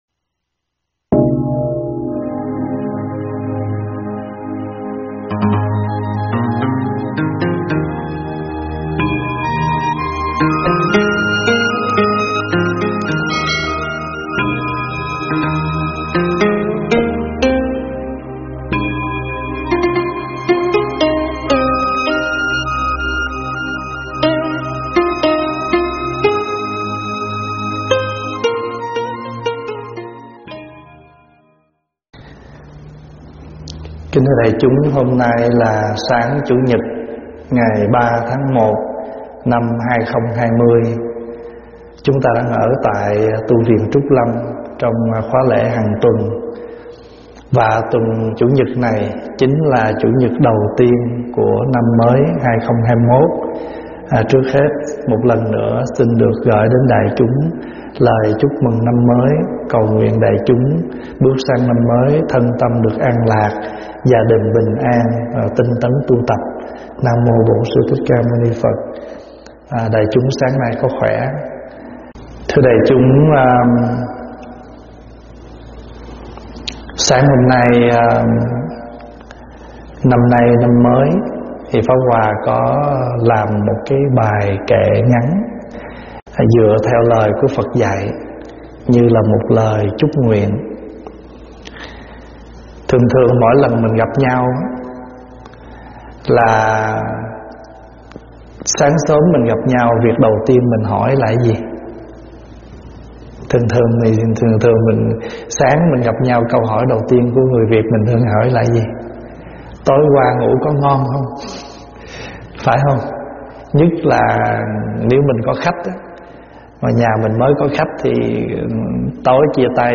thuyết pháp
tại Tv. Trúc Lâm